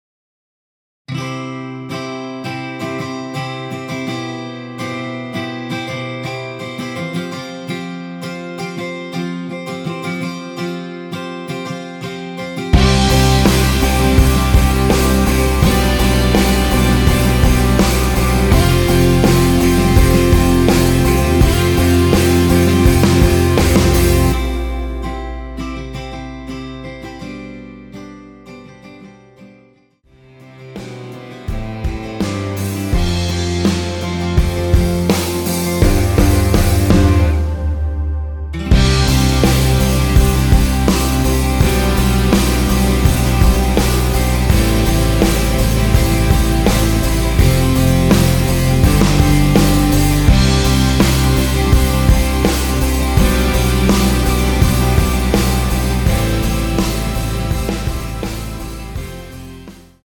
원키(1절앞+후렴)으로 진행되게 편곡된 MR입니다.
앞부분30초, 뒷부분30초씩 편집해서 올려 드리고 있습니다.
중간에 음이 끈어지고 다시 나오는 이유는